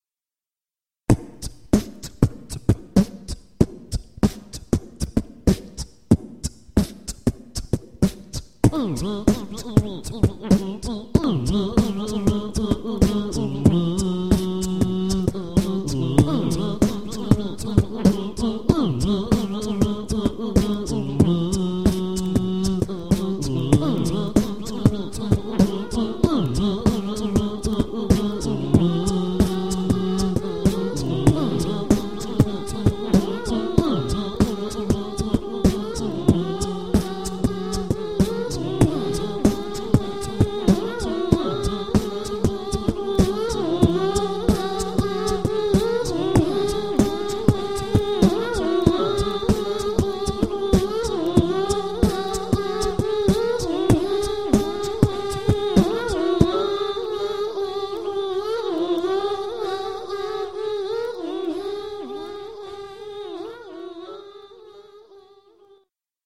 Форум российского битбокс портала » Реорганизация форума - РЕСТАВРАЦИЯ » Выкладываем видео / аудио с битбоксом » Баловство (Трек в Amiloop)